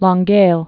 (lông-gāl)